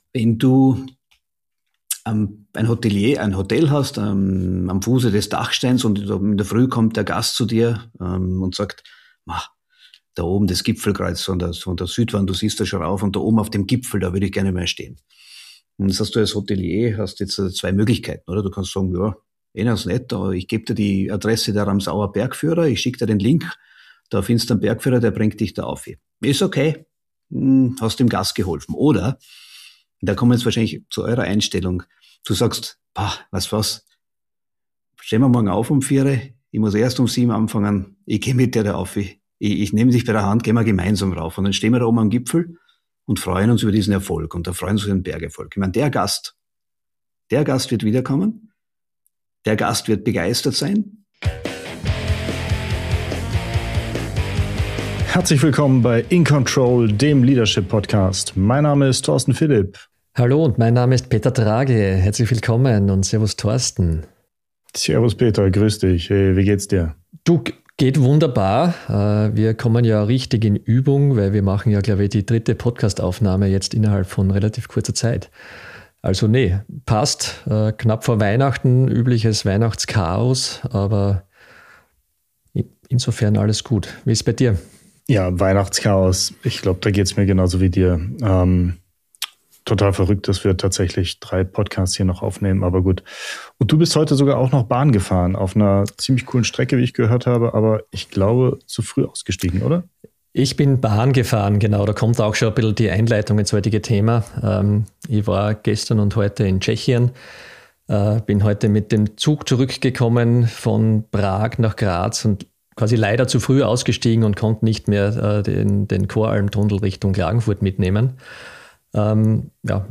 Wir sprechen über die Ausbildung und Entwicklung junger Menschen, die Rolle starker Führungsteams vor Ort und darüber, wie eine gemeinsame Vision in einer globalen Organisation klar vermittelt und gelebt wird. Ein offenes Gespräch über Verantwortung, Haltung, Fehlerkultur und persönliche Erfahrungen aus einer der zentralen Führungspositionen der österreichischen Außenwirtschaft.